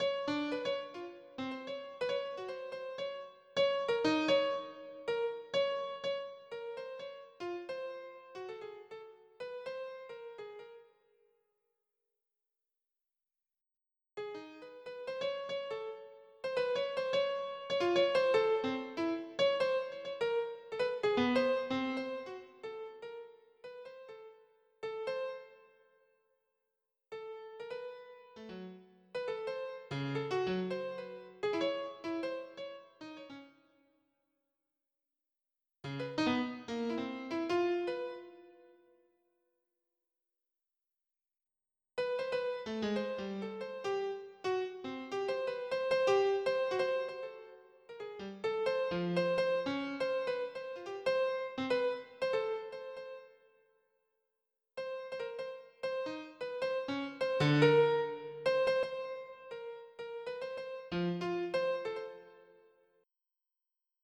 • Качество: 320, Stereo
без слов
пианино
море
Ещё и ветер неслабый!